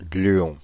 Ääntäminen
Ääntäminen France (Île-de-France): IPA: /ɡly.ɔ̃/ Paris: IPA: [ɡly.ɔ̃] Haettu sana löytyi näillä lähdekielillä: ranska Käännöksiä ei löytynyt valitulle kohdekielelle.